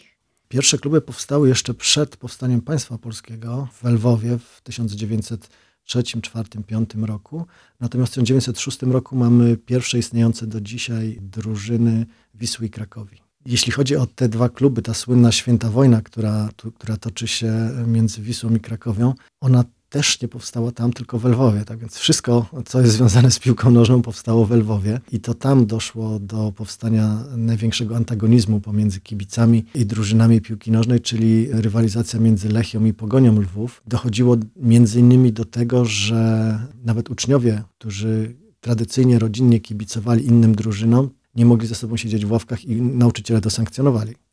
Historyk sportu: Piłka nożna to fenomen społeczny